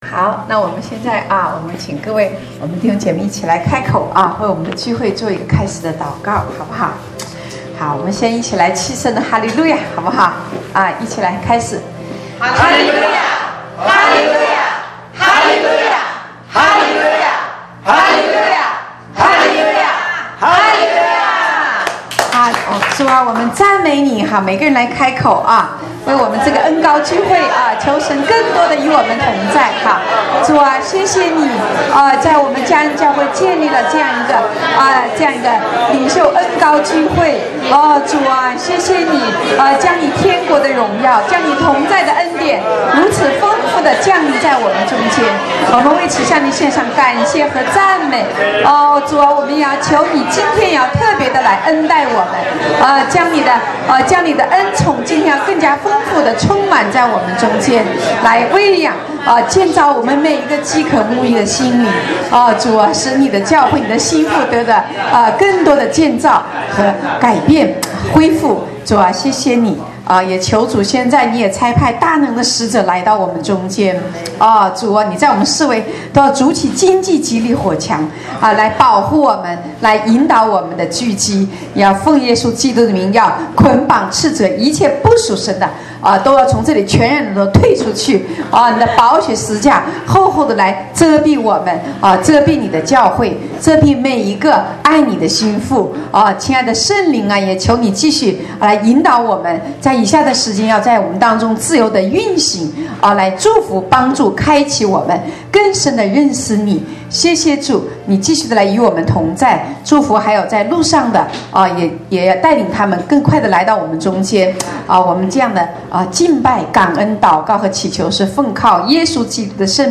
正在播放：--主日恩膏聚会录音（2014-11-09）